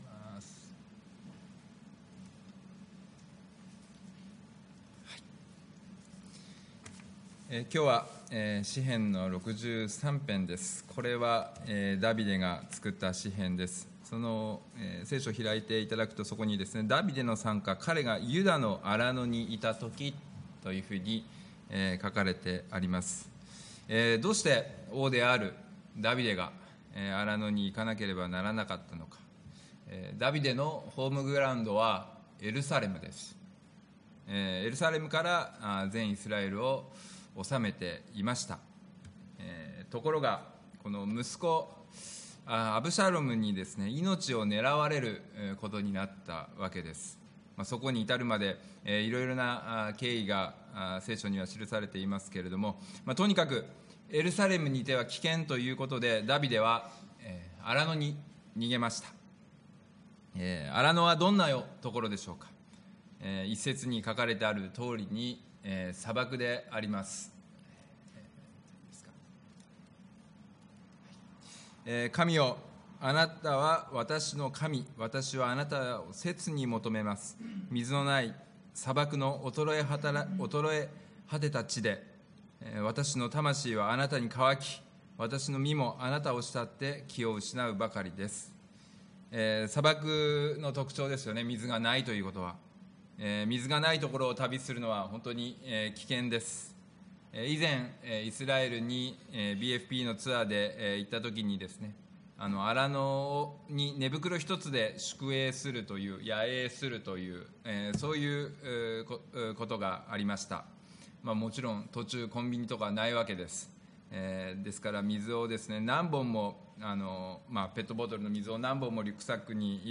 礼拝メッセージ(説教)